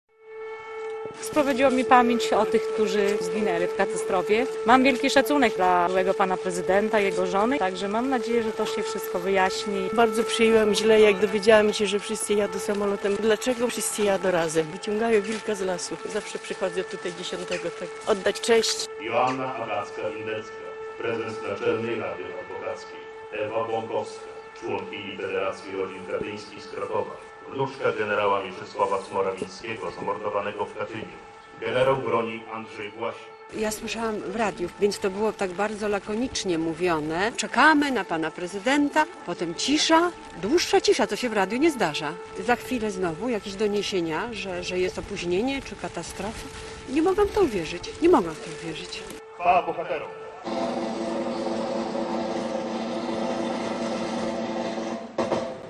Uroczystości w 6. rocznicę katastrofy smoleńskiej w Łomży - relacja